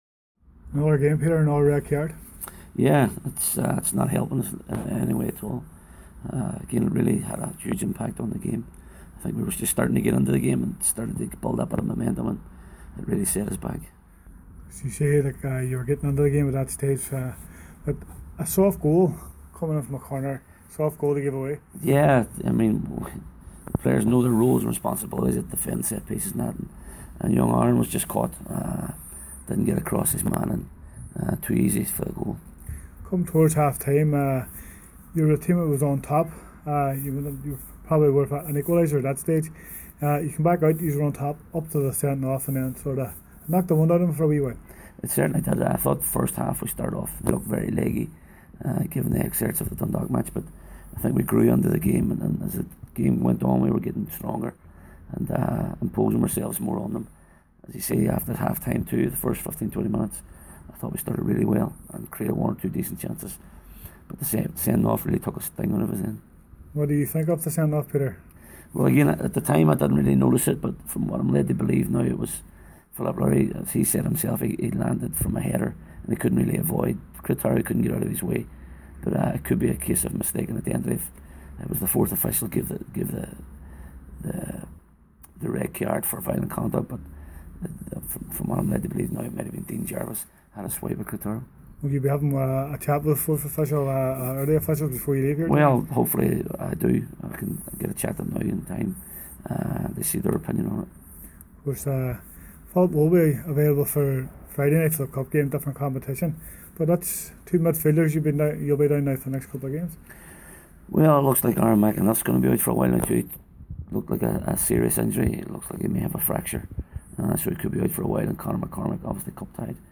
Aftermatch Interview